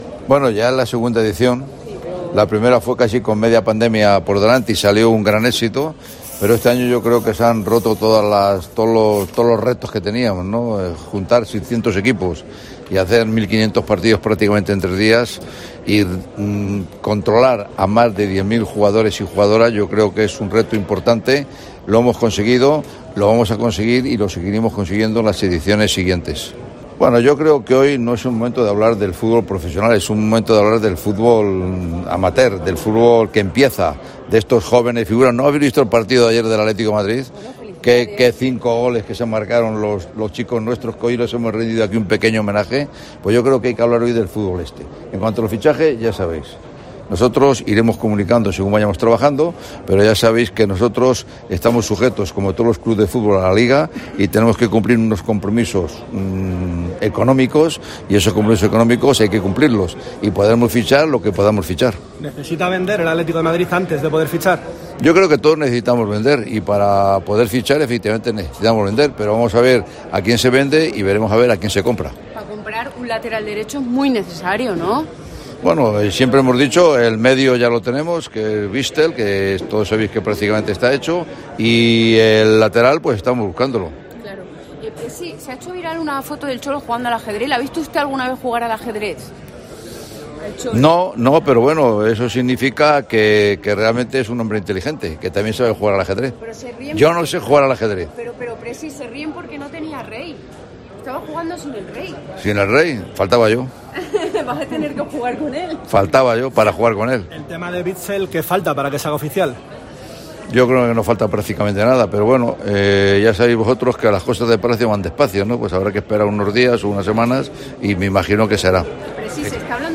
Presentación MADCUP 2022